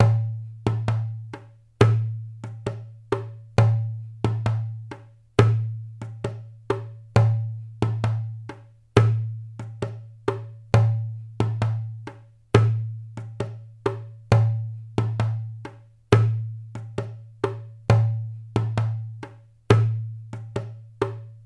关于轻敲鼓的声音的PPT模板_风云办公